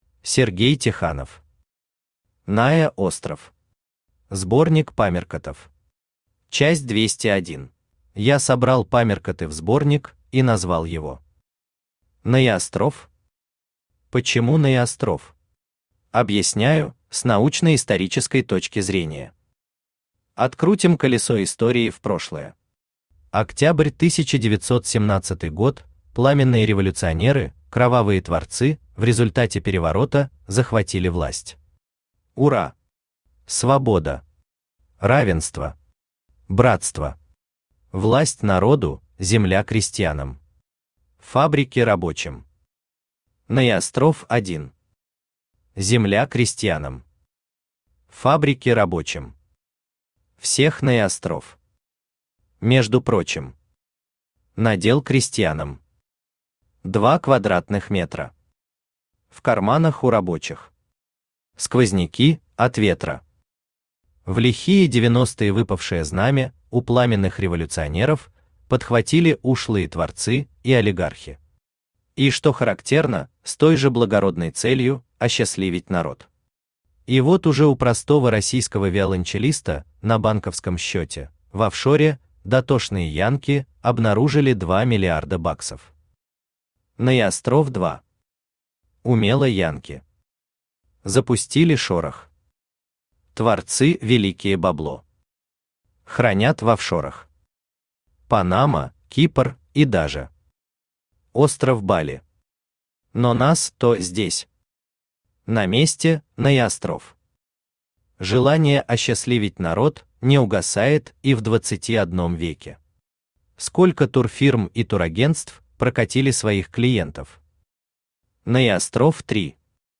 Аудиокнига НаеОстров. Сборник памяркотов. Часть 201 | Библиотека аудиокниг
Часть 201 Автор Сергей Ефимович Тиханов Читает аудиокнигу Авточтец ЛитРес.